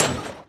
Sound / Minecraft / mob / blaze / hit3.ogg